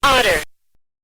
Spoken Word Otter
Animals
Spoken Word Otter.mp3